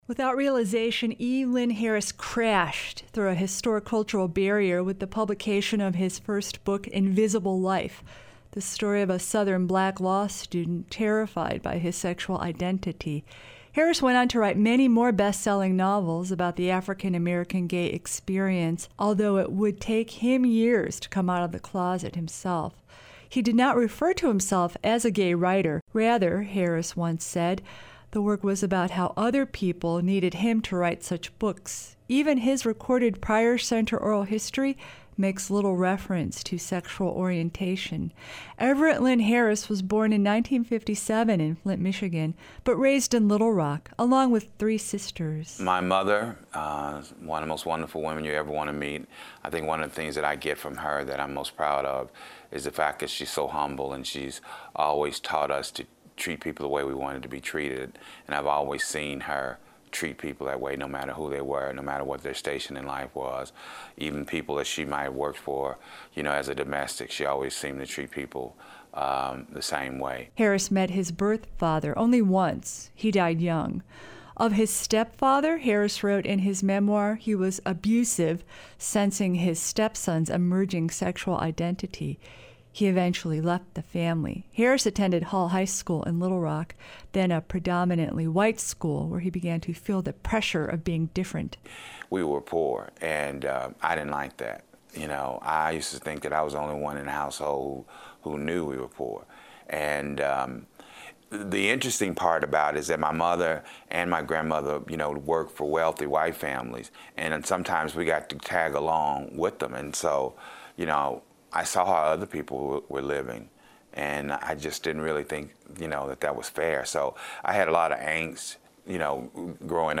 Pryor Center Feature: E. Lynn Harris E_Lynn_Harris.mp3 Several years before his sudden death, the Pryor Center for Arkansas Oral and Visual History conducted a formal interview with writer E. Lynn Harris.